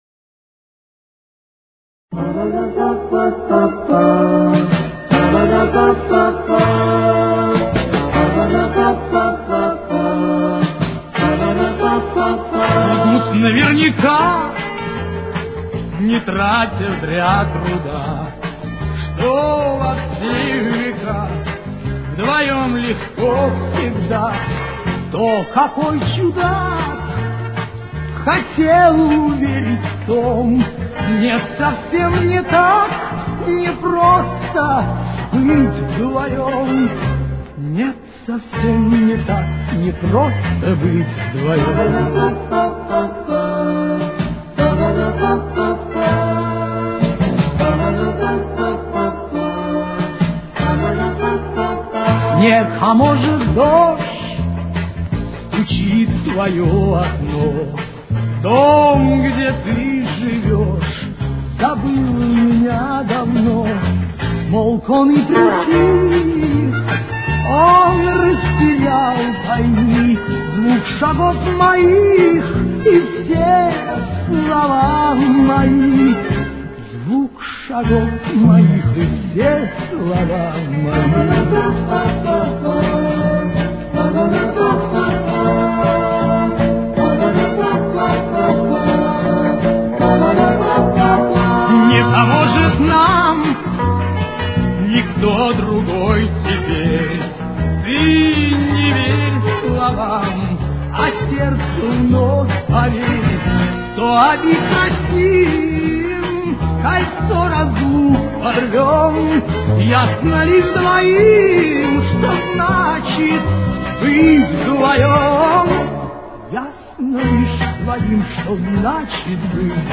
Тональность: Ре минор. Темп: 160.